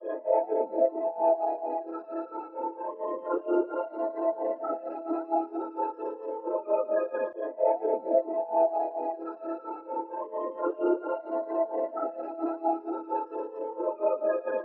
140 Bpm的阿拉伯rap铅字，G调
描述：跟着我阿拉伯语, 陷阱, 节日, 陷阱,肮脏, 领先。
Tag: 140 bpm Trap Loops Synth Loops 590.80 KB wav Key : G FL Studio